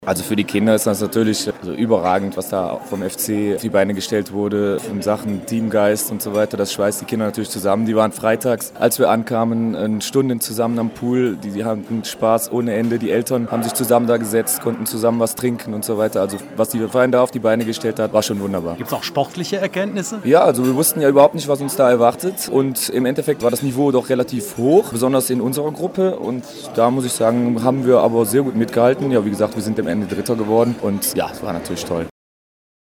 aus Klagenfurt (AUT)